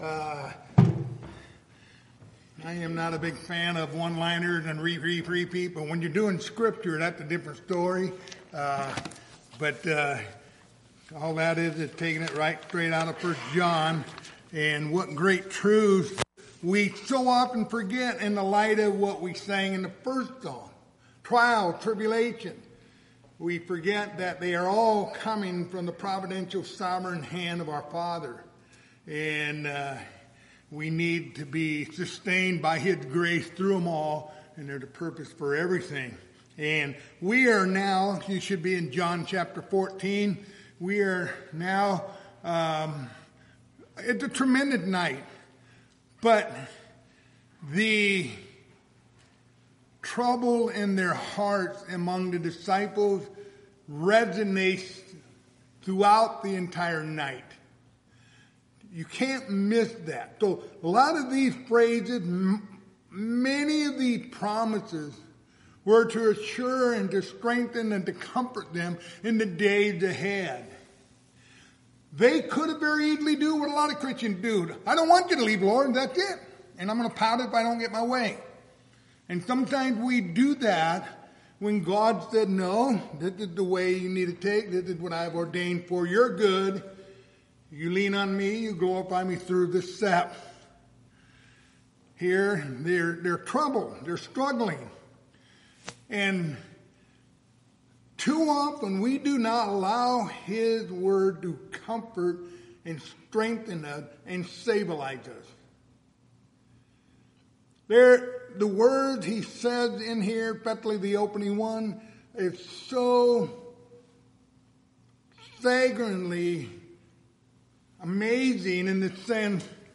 Passage: John 14:15-21 Service Type: Wednesday Evening